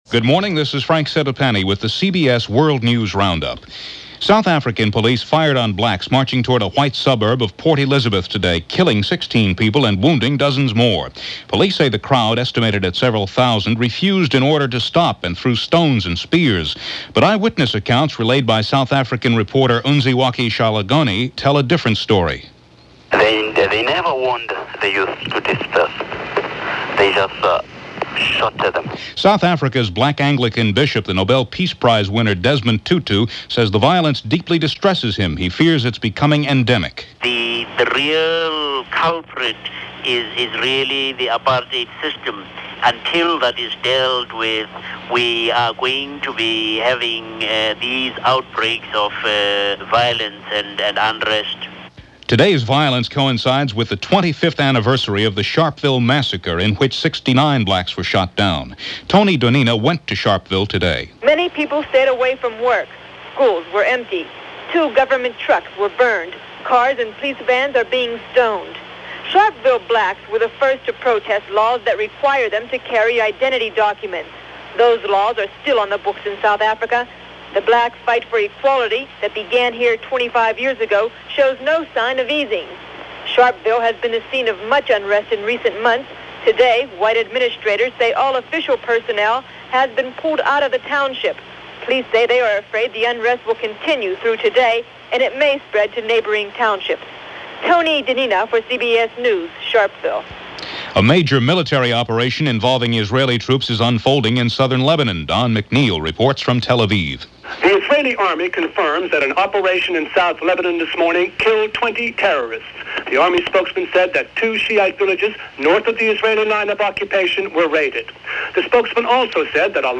And that’s a small chunk of what went on this rather chaotic March 21st in 1985 as presented by The CBS World News Roundup.